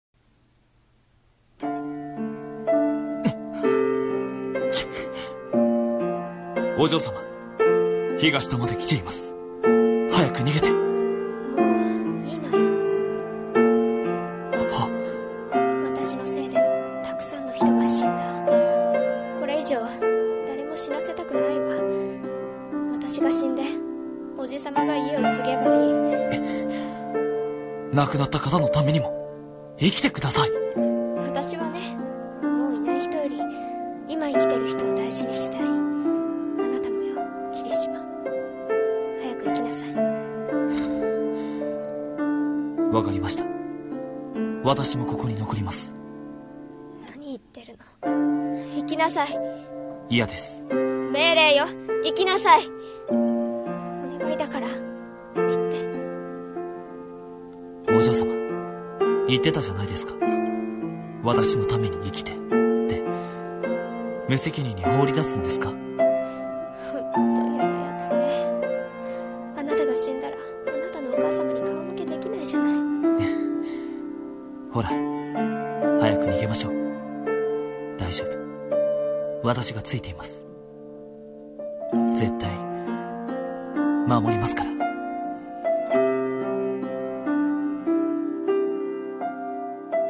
【声劇台本】後継者争い【２人声劇】